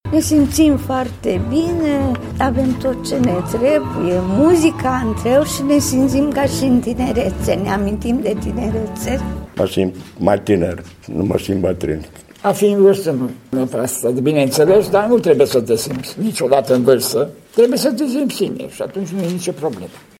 S-au interpretat poezii, s-a făcut haz de necaz și s-a cântat, într-o atmosferă prietenească.